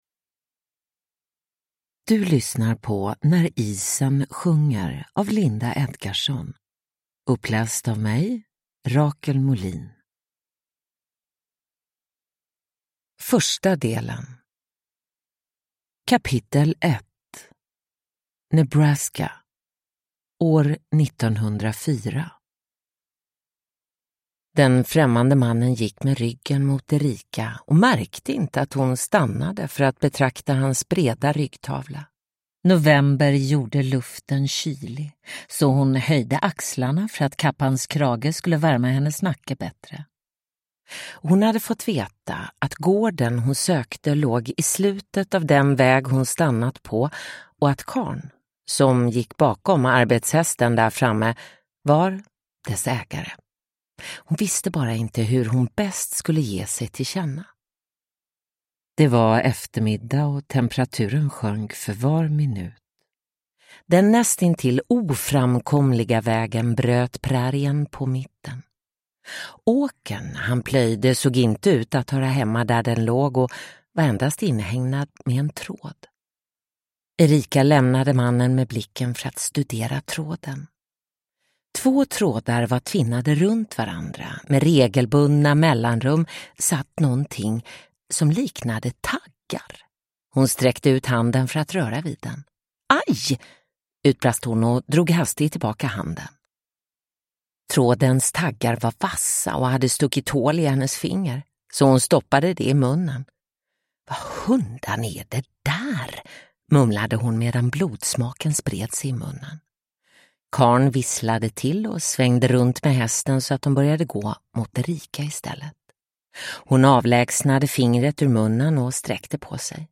När isen sjunger (ljudbok) av Linda Edgarsson